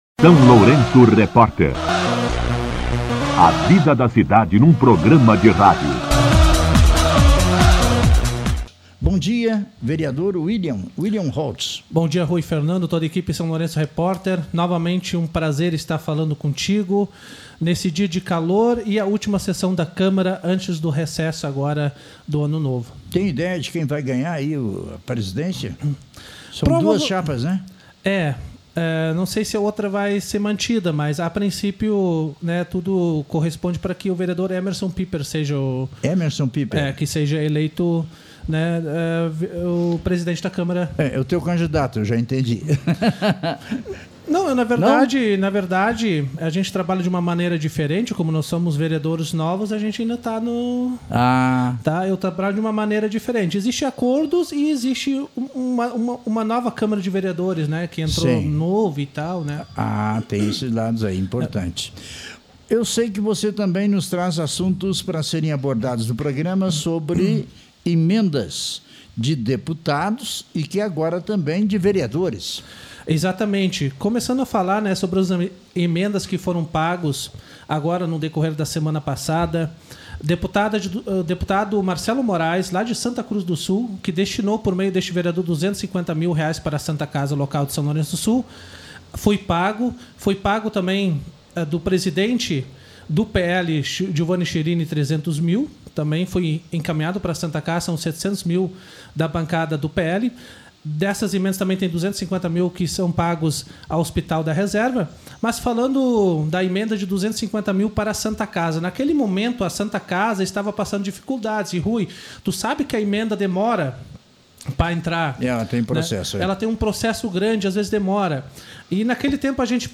Entrevista com O vereador Willian Holz
O vereador Willian Holz esteve no SLR RÁDIO nesta sexta-feira (22) para falar sobre emendas do PL que contribuíram para o pagamento do 13º salário dos servidores da Santa Casa de Misericórdia de São Lourenço do Sul.